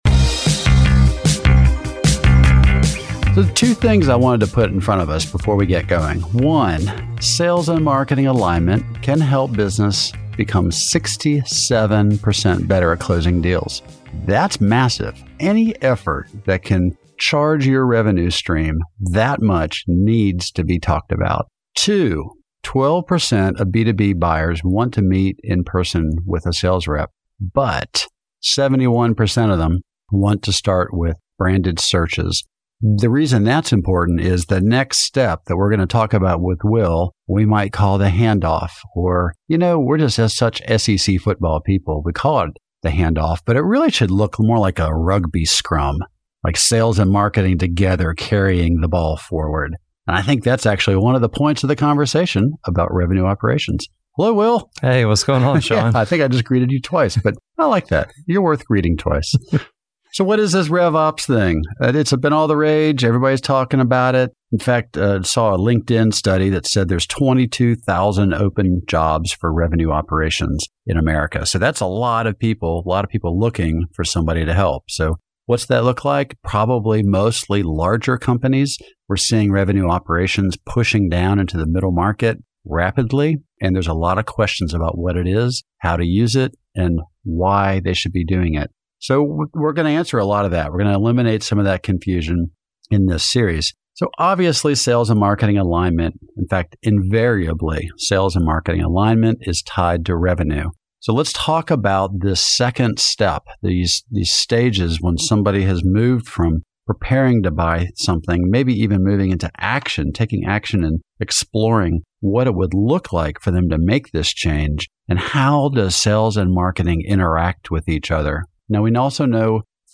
Sales and marketing alignment is tied to revenue, so today we’re talking about the stage when someone has moved from preparing to buy to actually taking action.